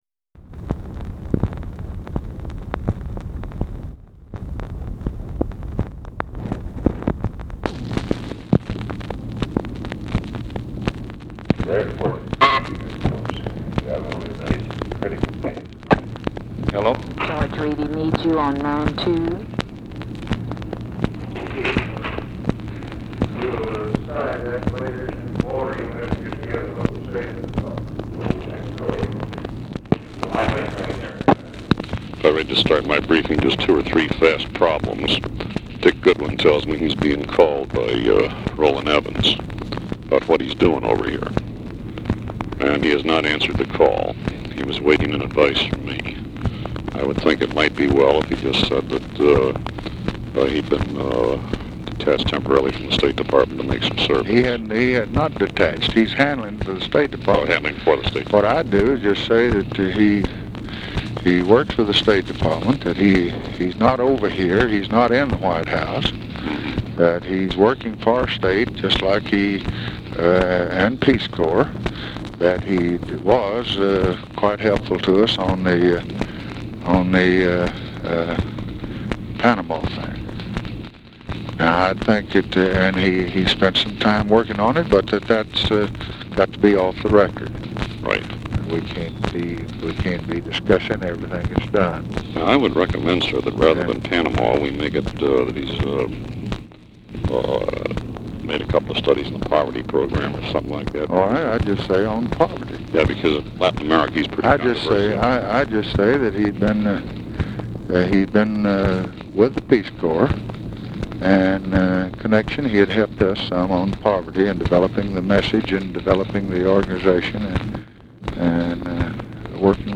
Telephone conversation # 2874, sound recording, LBJ and GEORGE REEDY, 4/7/1964, 11:50AM | Discover LBJ
Title Telephone conversation # 2874, sound recording, LBJ and GEORGE REEDY, 4/7/1964, 11:50AM Archivist General Note VERY BRIEF INTERRUPTION BY UNIDENTIFIED FEMALE 11:00 INTO CONVERSATION, Previously C-SAN.
Format Dictation belt
Location Of Speaker 1 Oval Office or unknown location
Specific Item Type Telephone conversation Subject Appointments And Nominations Diplomacy Latin America Lbj Personal Lbj Travel Presidency Press Relations White House Administration